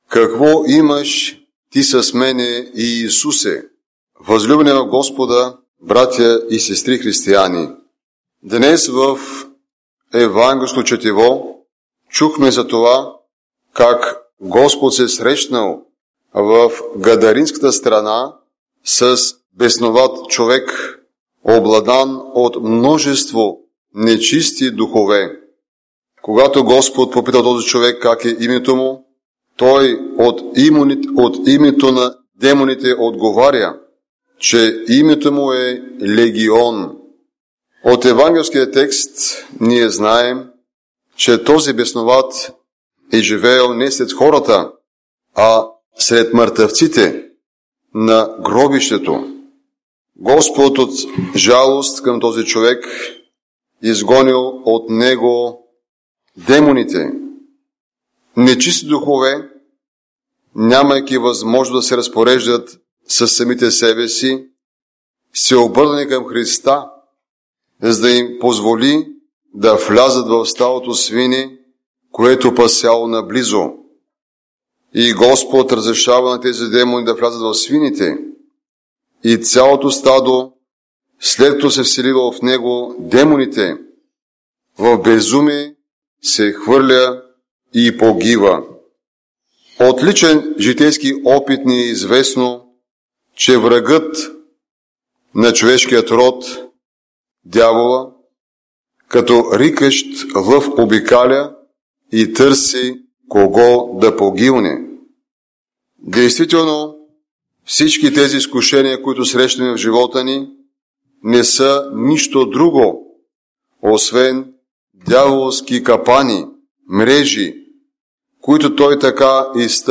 Неделни проповеди